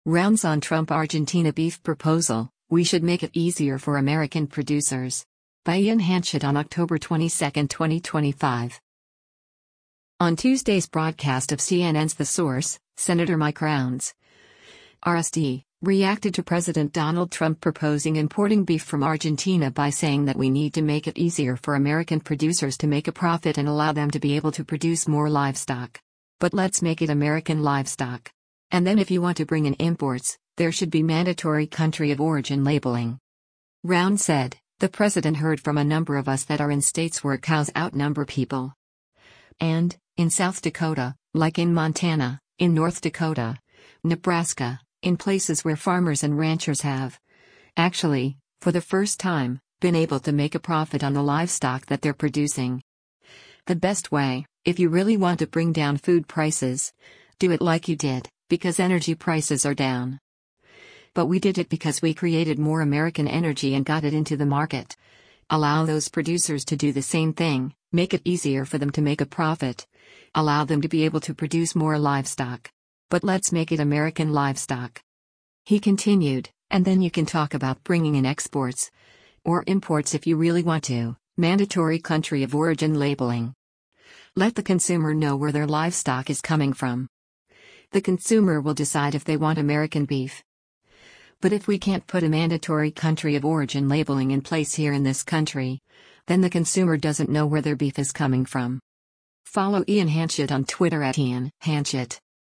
On Tuesday’s broadcast of CNN’s “The Source,” Sen. Mike Rounds (R-SD) reacted to President Donald Trump proposing importing beef from Argentina by saying that we need to make it easier for American producers to make a profit and “allow them to be able to produce more livestock.